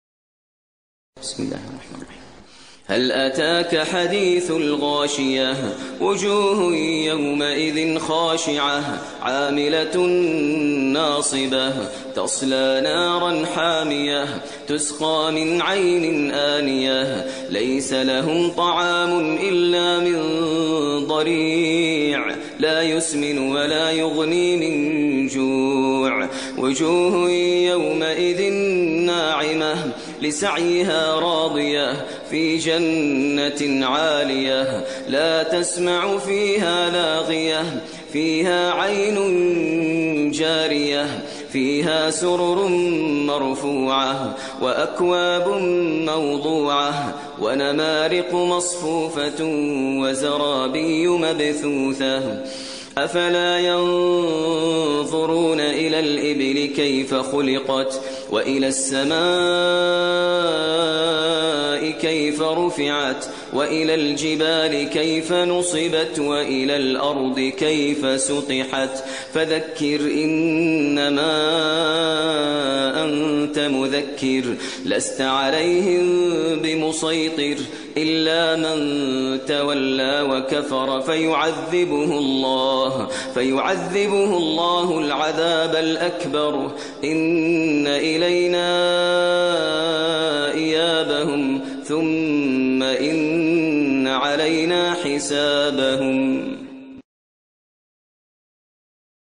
ترتیل سوره غاشیه با صدای ماهر المعیقلی